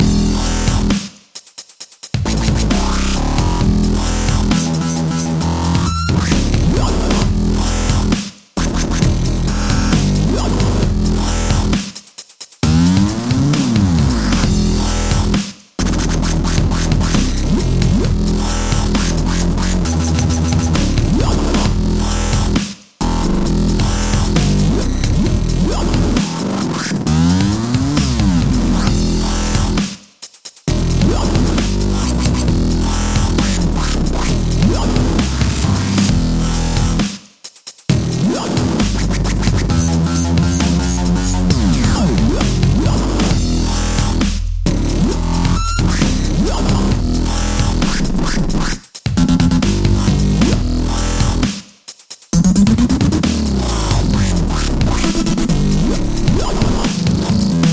This series of quick tips will outline how you can use the ever powerful NI Massive synth to create techy basslines used by artists such as Skrillex.
Here is an example of the kind of sound you can expect to end up with at the end of this series: